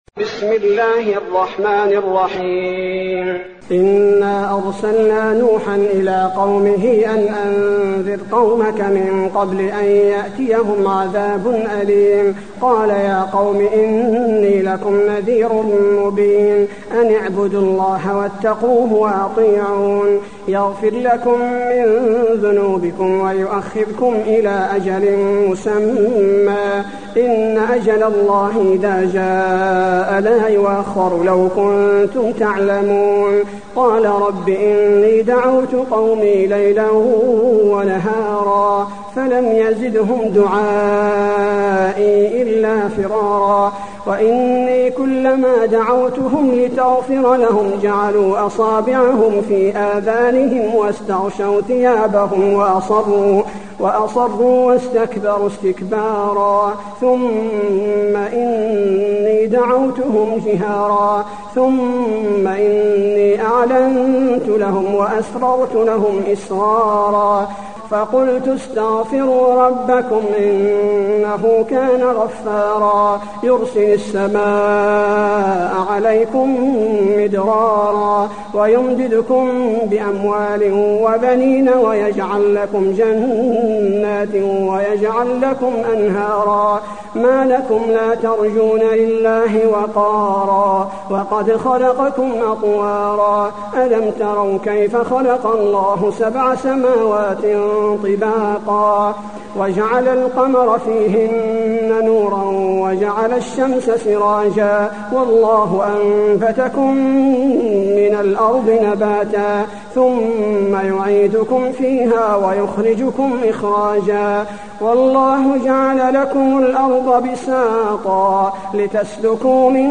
المكان: المسجد النبوي نوح